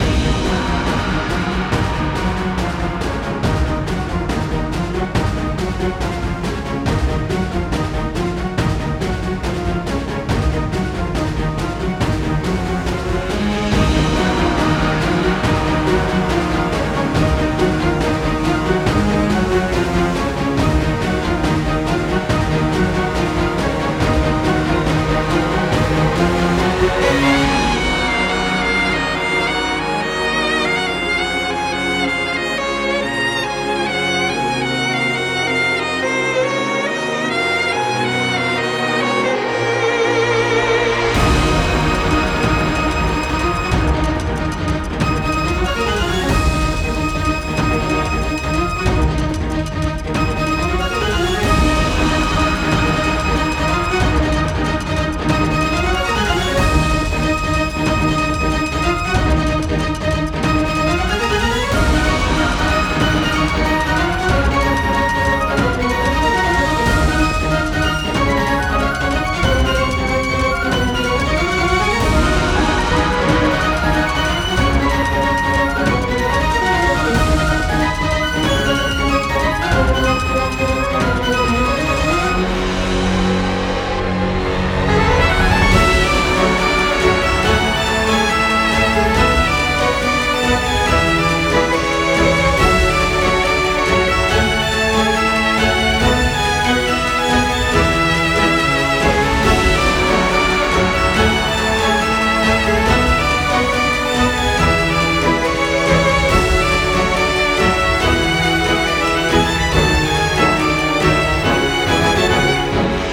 白熱する壮大ボス戦オーケストラBGM